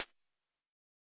Index of /musicradar/ultimate-hihat-samples/Hits/ElectroHat A
UHH_ElectroHatA_Hit-09.wav